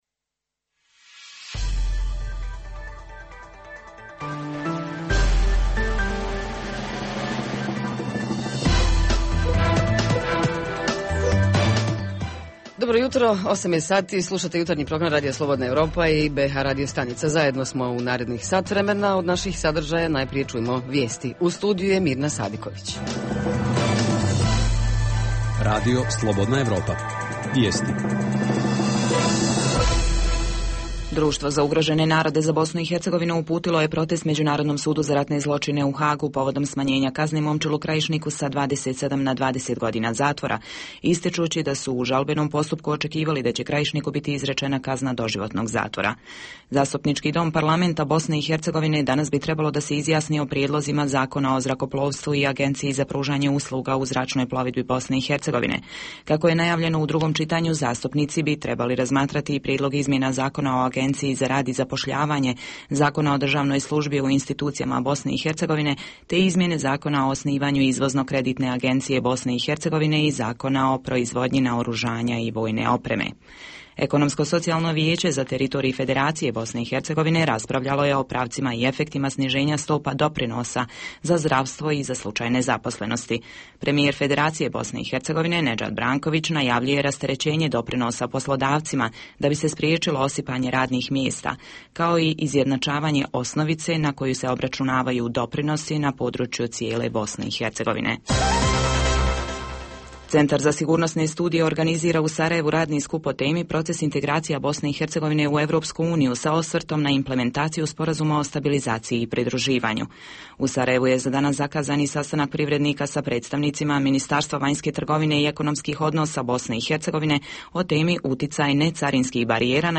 Jutarnji program za BiH koji se emituje uživo. Ovog jutra govorimo o nasilju u porodici i kako ga spriječiti.